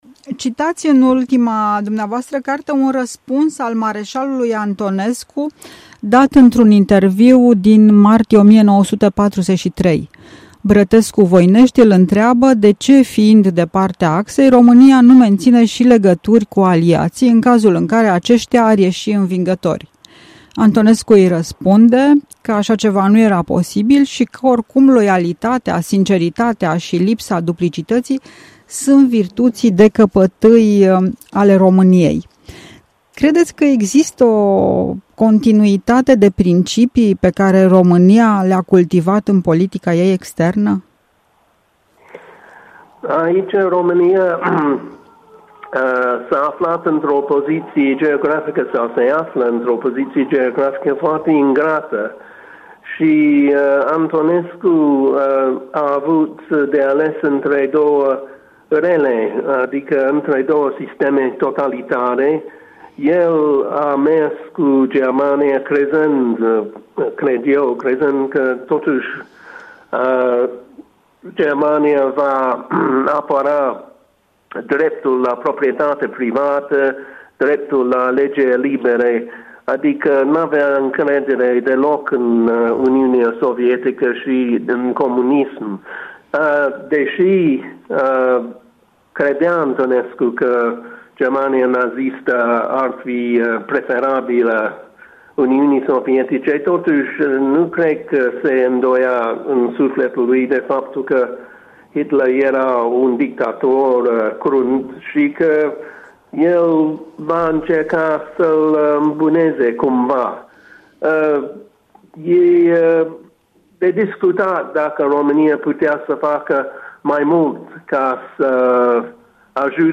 Interviu cu Dennis Deletant